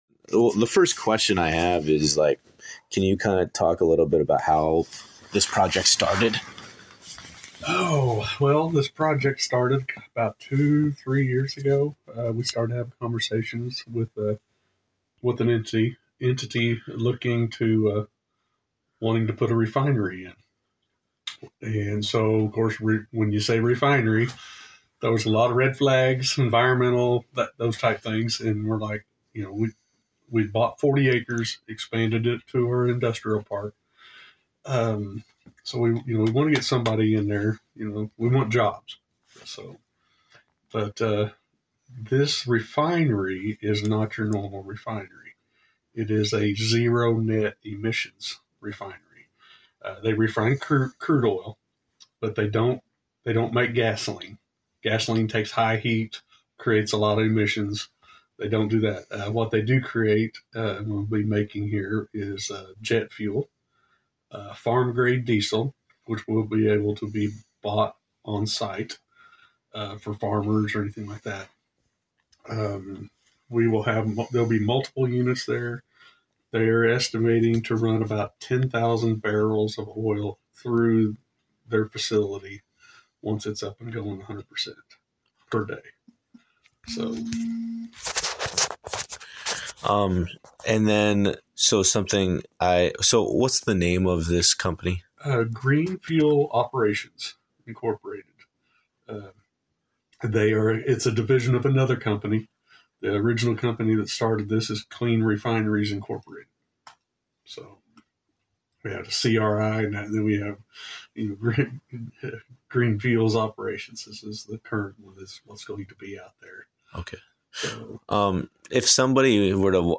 Dewey City Manager Kevin Trease sat down with Bartlesville Radio to explain the project.
Kevin trease full interview(2).mp3